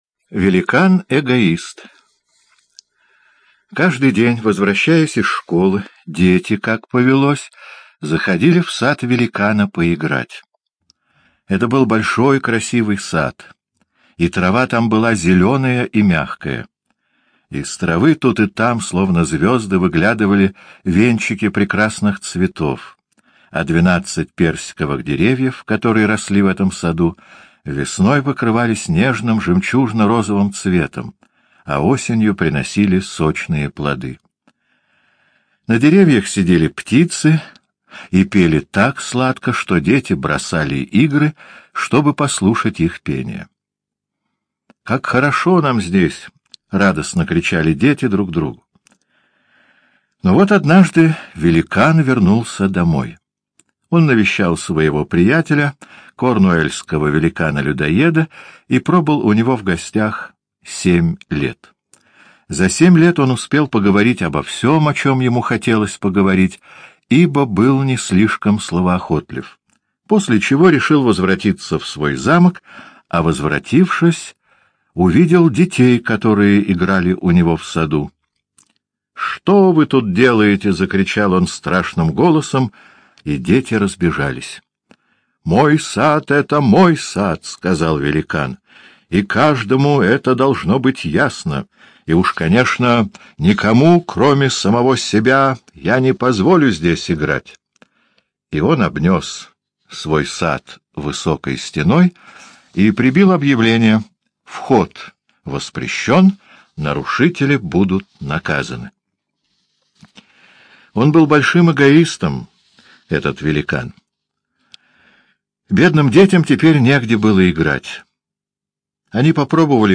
Великан-эгоист — аудиосказка Уайльда О. Сказка о возрождении души великана, который запретил детям играть в своем саду и там воцарилась зима.